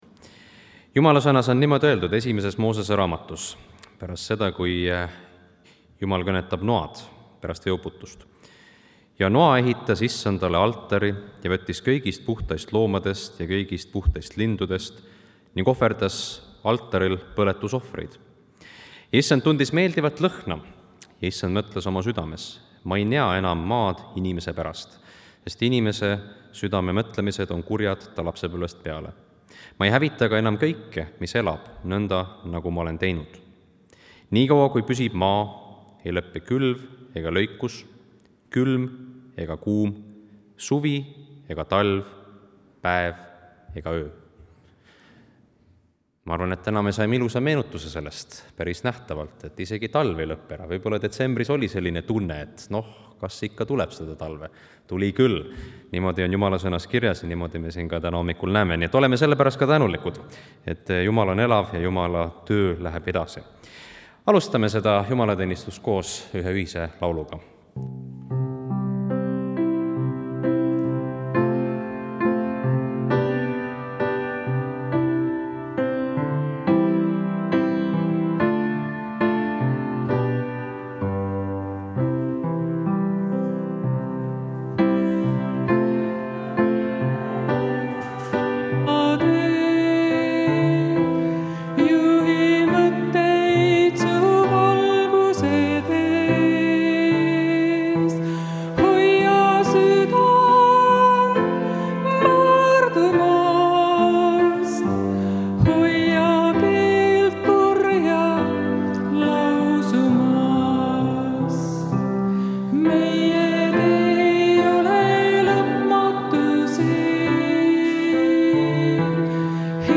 Pühakirja lugemine: Lk 3:15-22
Jutlus